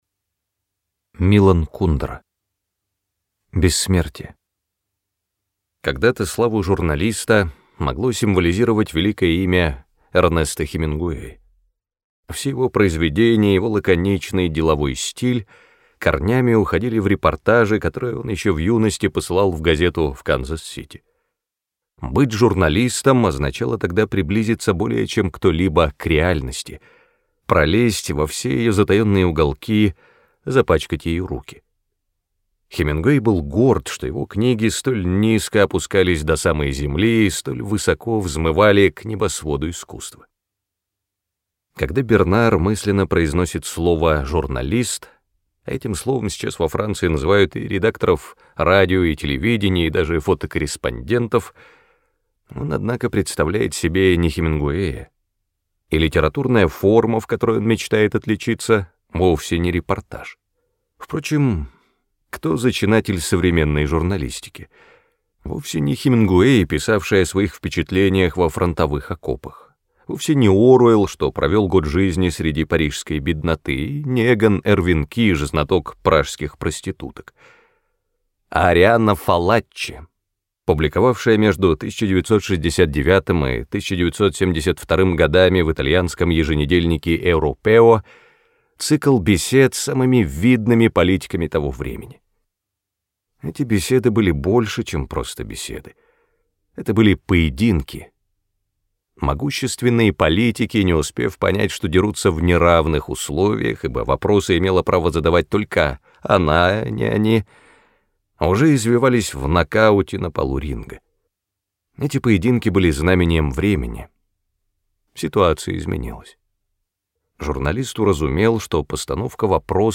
Аудиокнига Бессмертие | Библиотека аудиокниг